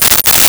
Coins Shake In Hand 01
Coins Shake in Hand 01.wav